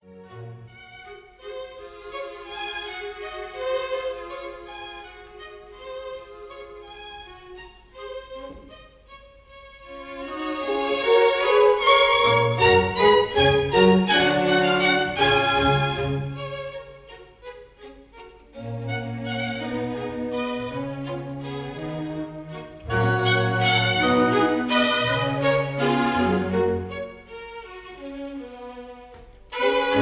Recorderd live at the 1st Aldeburgh
Jubilee Hall, Aldeburgh, Suffolk